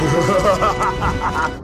One of the most iconic Arnold Schwarzenegger quotes.
"(Frostie laughs)"
frostie_laughs.mp3